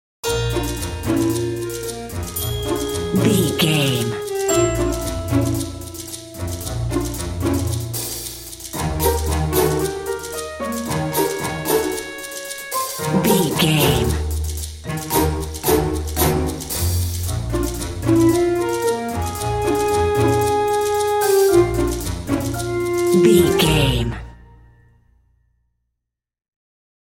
Uplifting
Lydian
B♭
flute
oboe
strings
orchestra
cello
double bass
percussion
cheerful
quirky